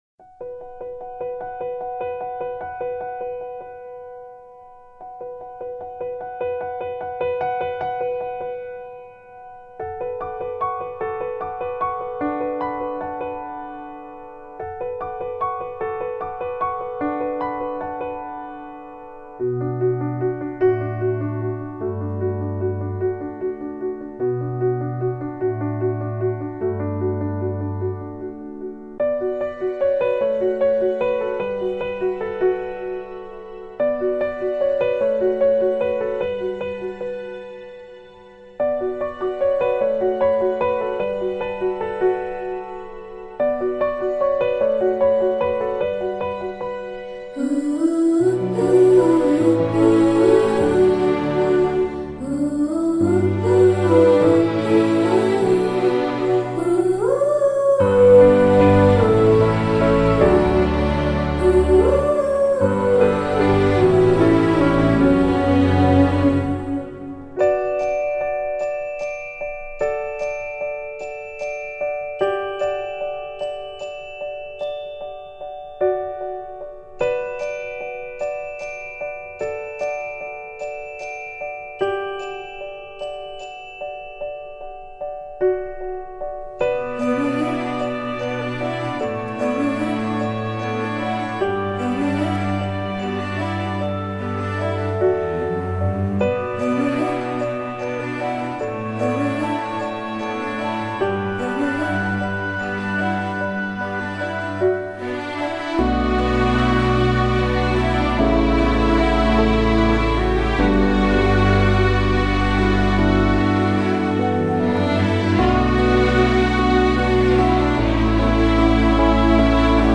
Bollywood Mp3 Music 2018